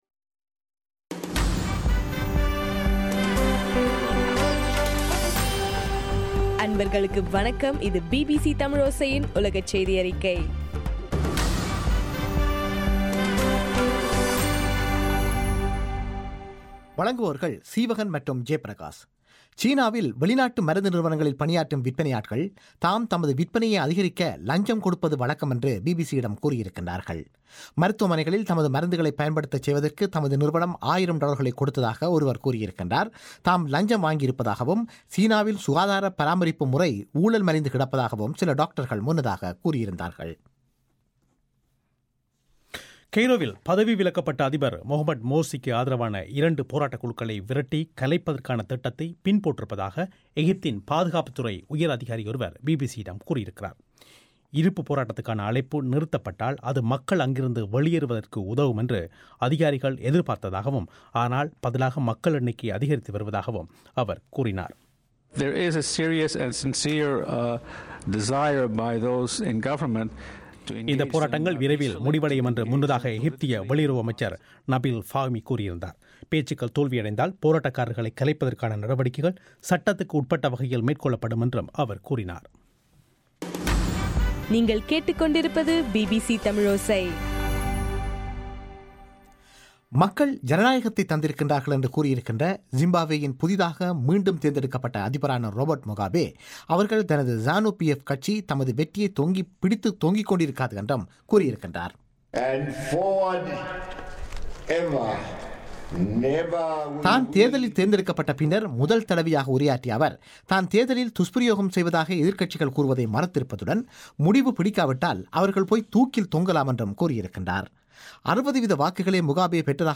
ஆகஸ்ட் 12 பிபிசியின் உலகச் செய்திகள்